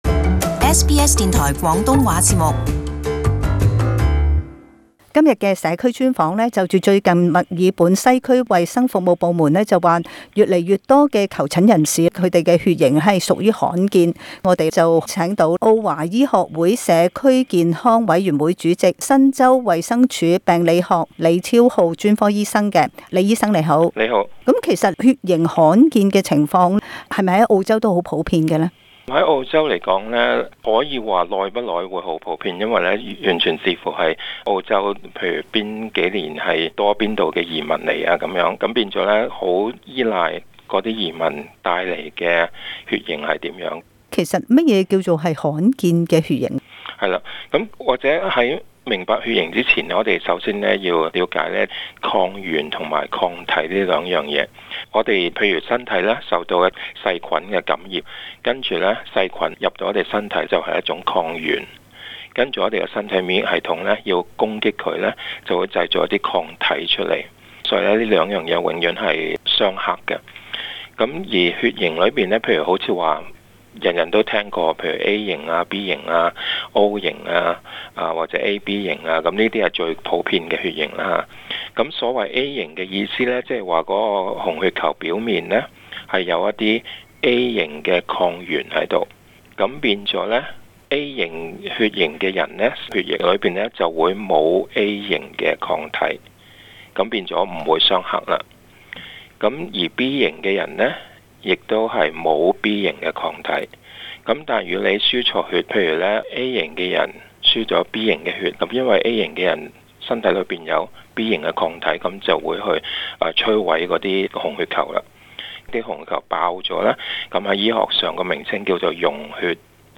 【社區專訪】罕見血型求診者上升